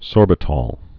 (sôrbĭ-tôl, -tōl, -tŏl)